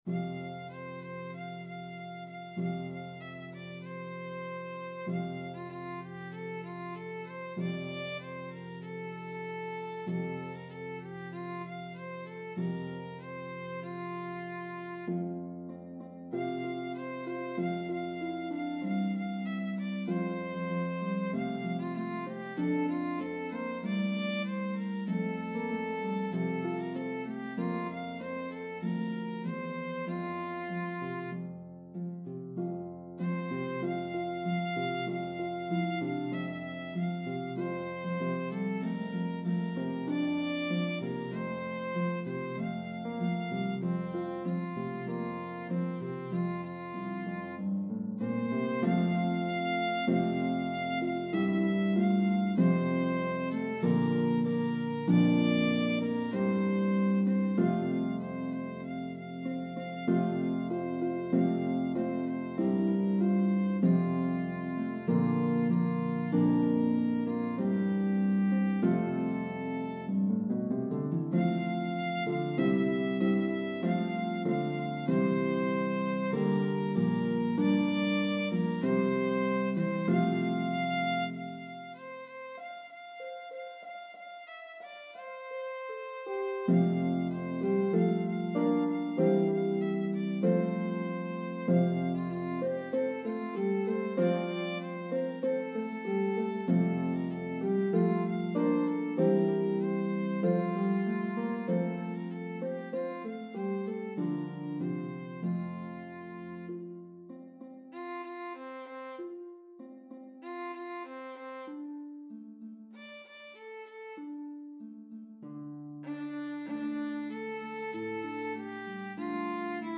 a medley of traditional English & Irish Carols